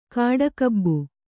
♪ kāḍakabbu